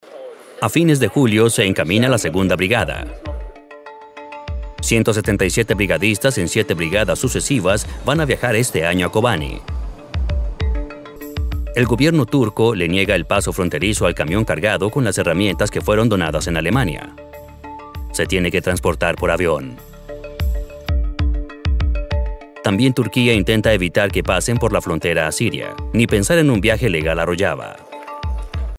Mature Adult, Adult, Young Adult
VOICEOVER GENRE documentary NARRATION
character sophisticated